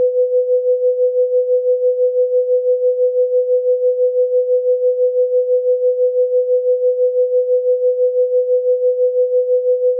Efecto de Trémolo
El efecto que se consigue es que el sonido suba y baje rápidamente.
En el siguiente enlace se puede escuchar el efecto de Trémolo, aunque no sea un efecto que se note demasiado si se aprecia que la amplitud del sonido sube y baja rápidamente.
Amplitudenmodulation.wav